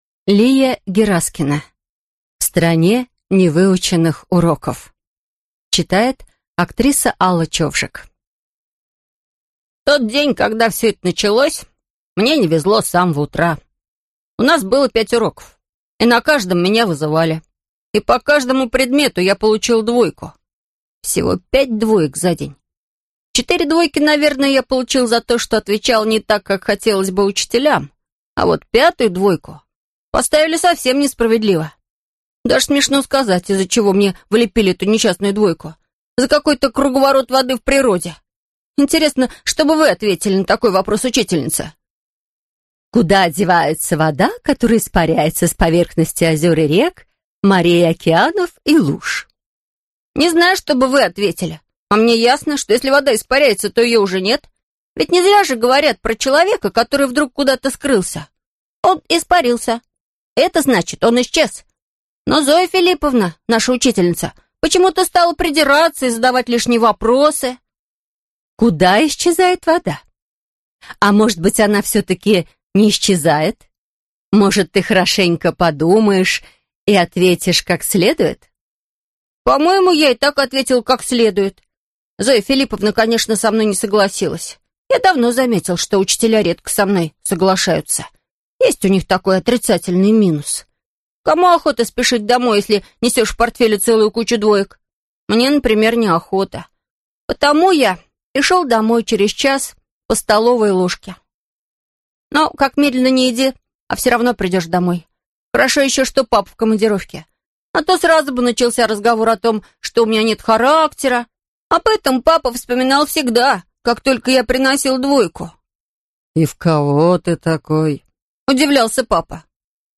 Аудиокнига В стране невыученных уроков | Библиотека аудиокниг